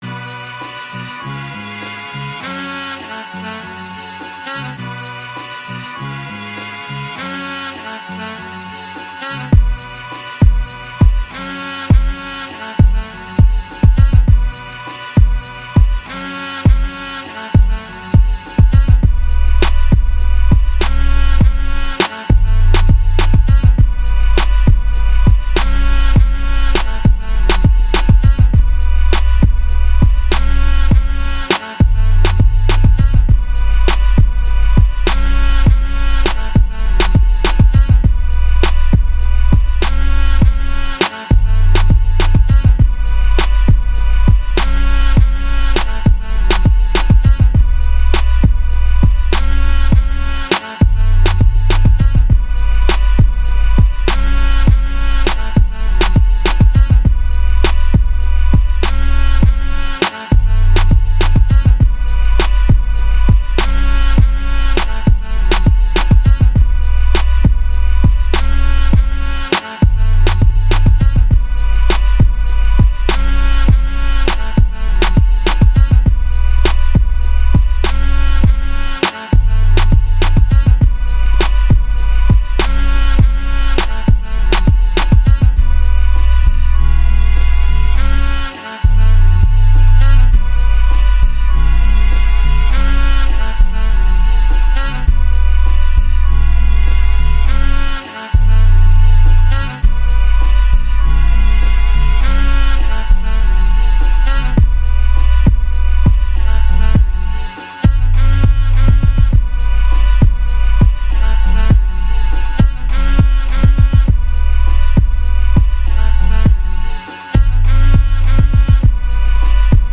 101 BPM
Music / Rap
This one is pretty relaxed, liked it.